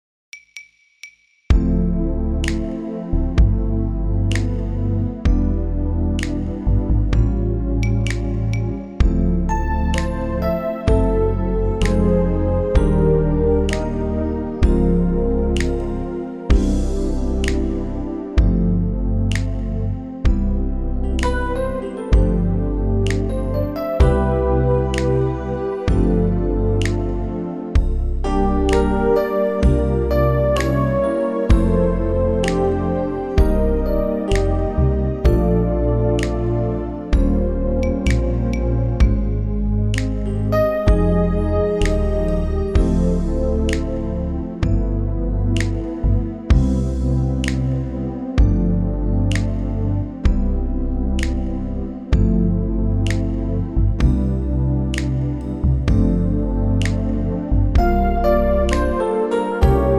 SOLO SYNTH REMOVED!
MP3 NO SOLO DEMO:
key - C - vocal range - C to D
Super smooth arrangement